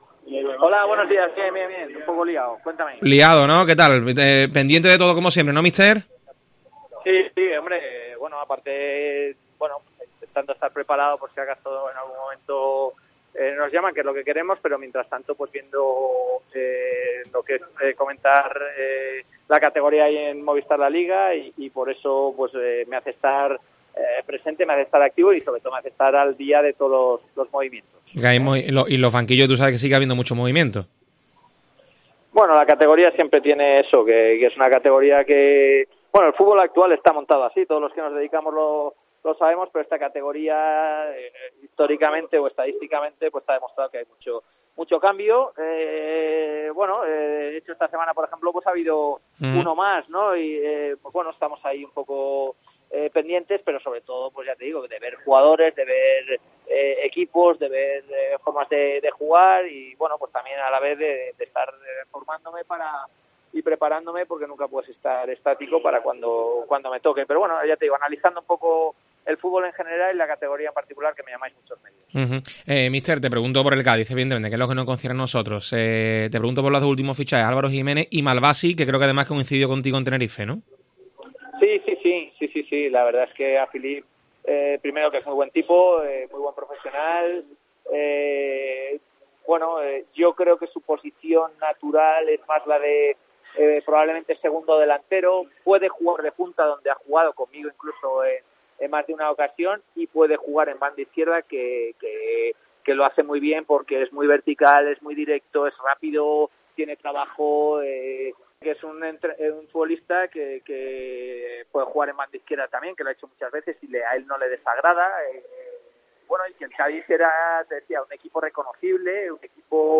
El técnico explica, como primer análisis, en Deportes COPE Cádiz que "el Cádiz CF ha mejorado".